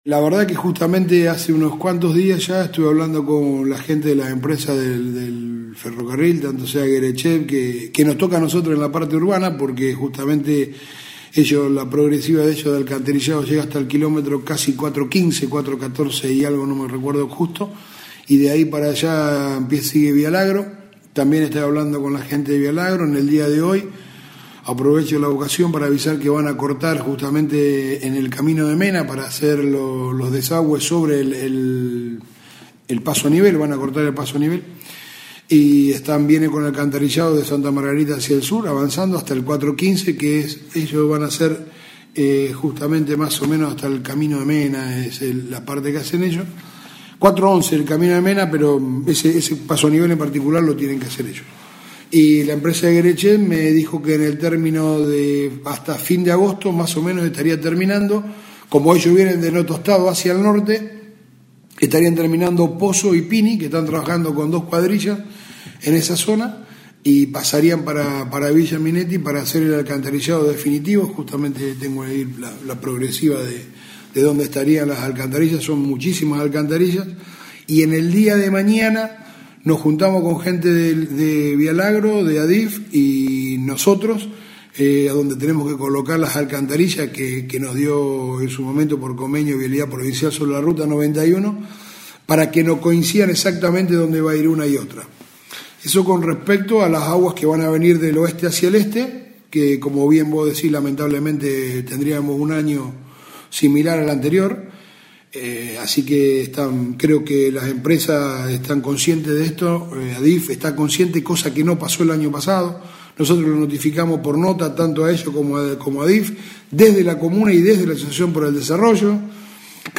En diálogo con Radio EME, hizo referencia a la futura sucursal de Banco Nación, la habilitación de la sala de faena, la nueva unidad móvil y el equipamiento del para el CIC, el comienzo de la obra de la ciclovía, el estado de las calles y su reparación, la necesidad de mejoras en el basural, y los trabajos que se hacen para tratar de evitar volver a sufrir inconvenientes en caso de lluvias.